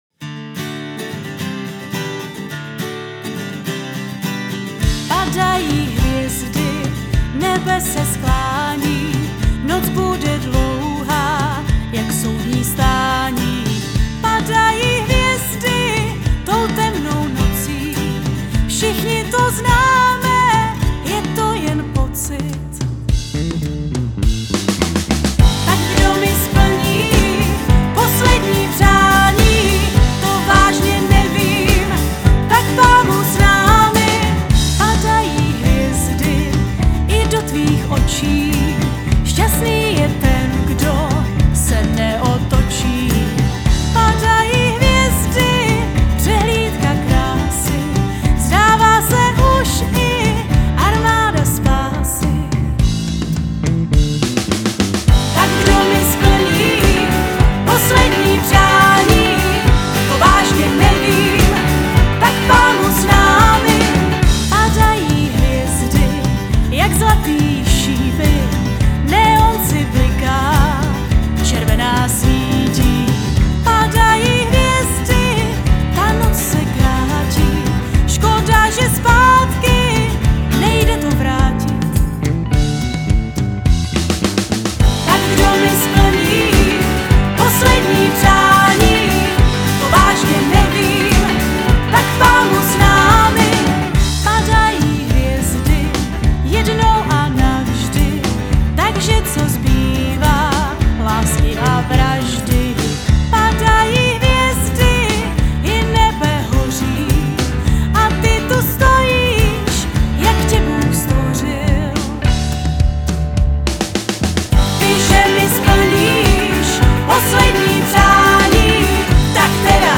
zpěv